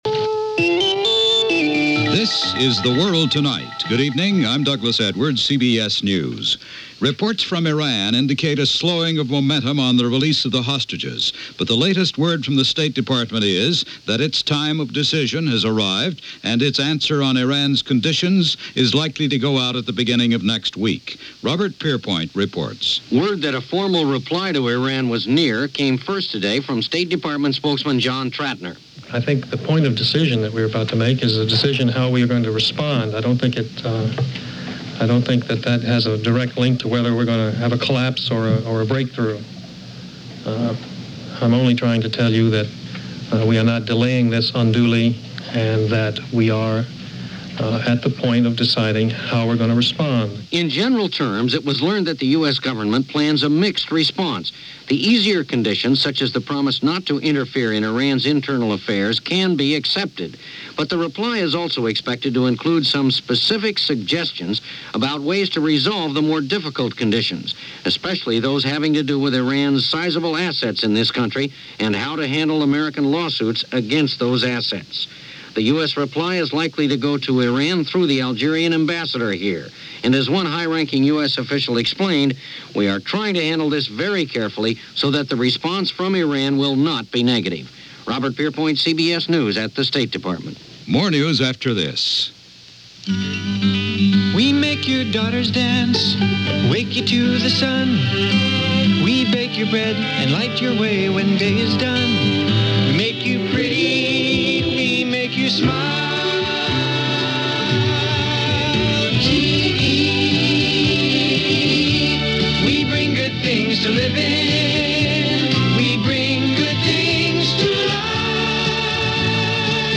And that’s how much of it went, this post-election November 7, 1980 as reported by Douglas Edwards and The World Tonight from CBS Radio.